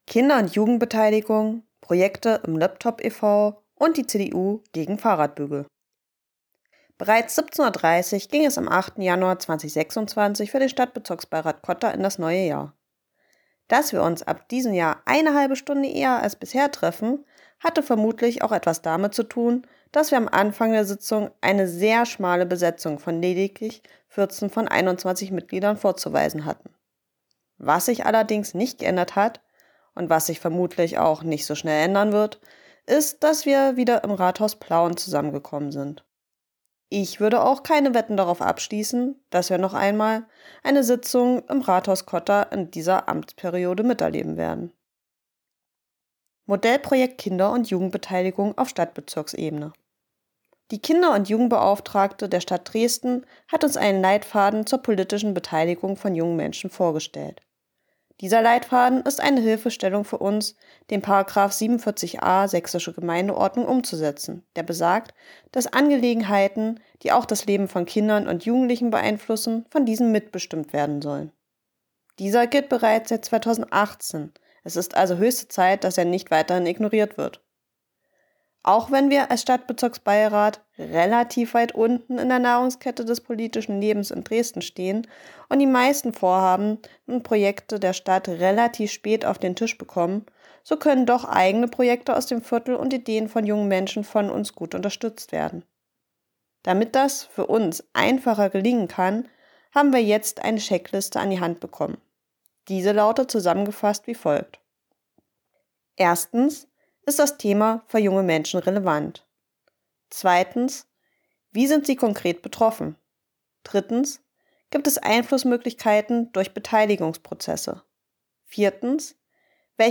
berichtet wieder für uns von der SBR-Sitzung.